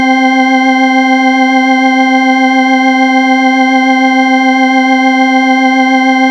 Eighties Thin Organ.wav